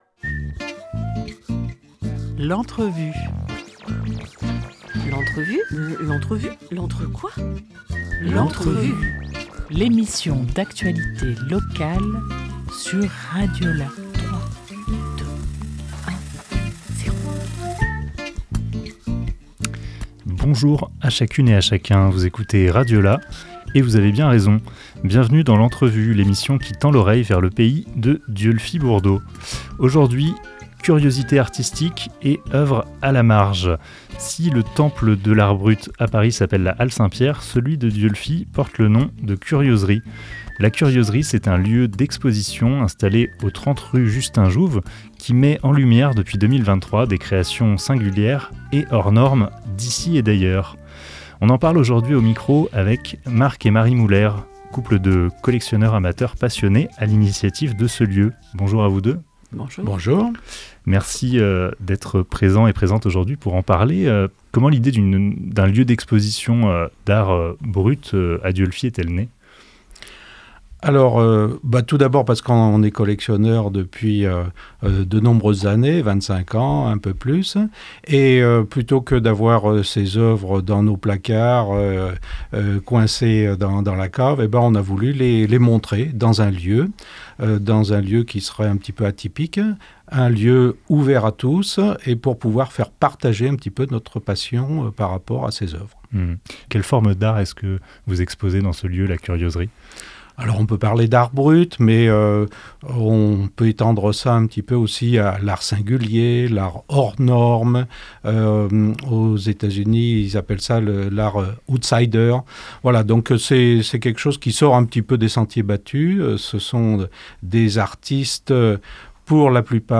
12 décembre 2024 11:34 | Interview